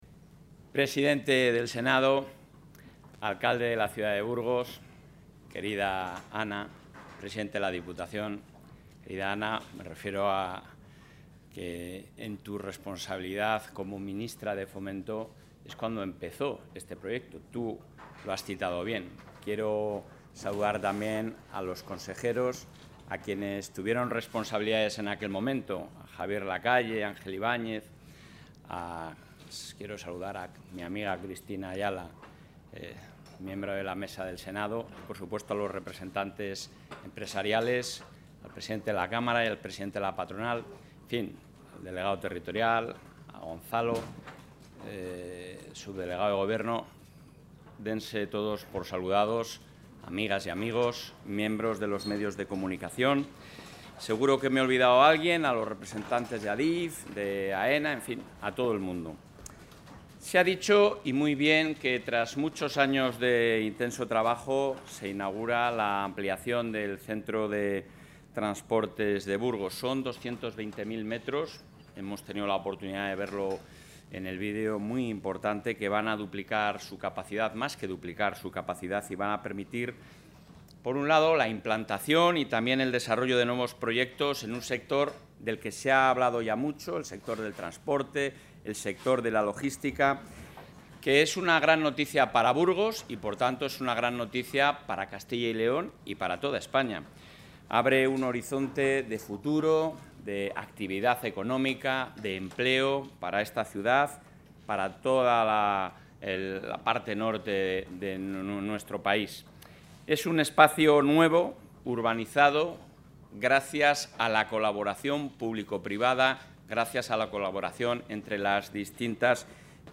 Intervención del presidente de la Junta.
En el acto de inauguración, el presidente de la Junta de Castilla y León, Alfonso Fernández Mañueco, ha señalado que la ampliación del Centro de Transporte de la ciudad de Burgos significa 220.000 nuevos metros cuadrados, que duplican su capacidad, permitiendo, por tanto, la implantación y desarrollo de nuevos proyectos en el sector del Transporte, la Logística y la Exportación.